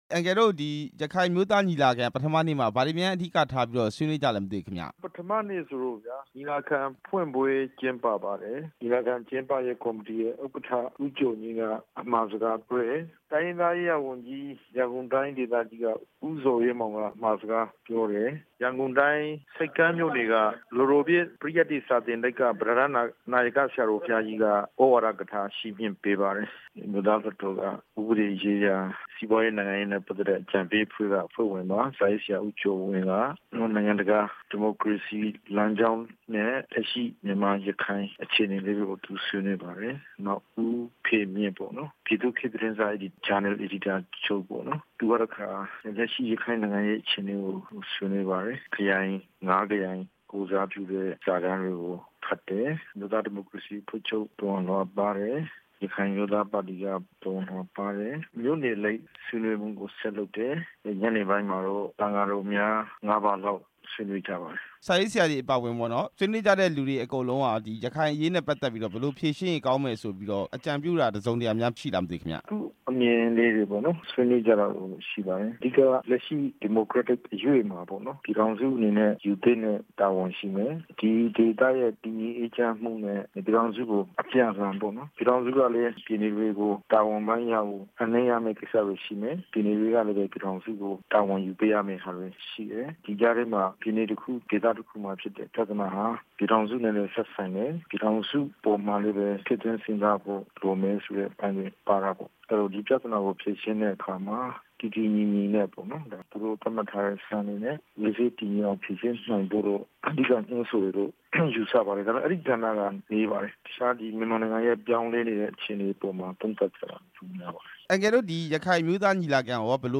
ဒေါက်တာအေးမောင်နဲ့ မေးမြန်းချက်